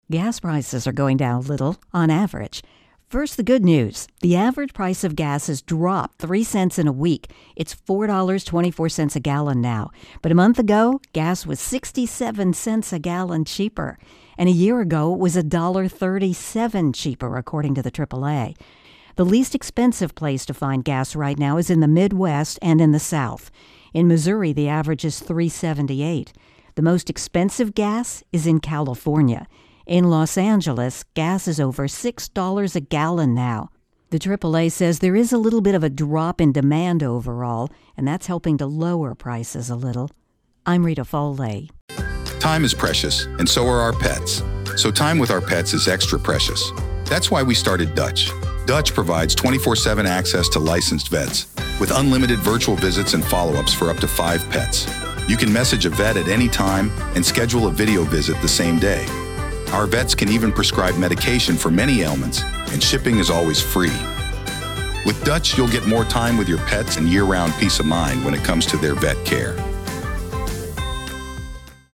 Gas voicer and intro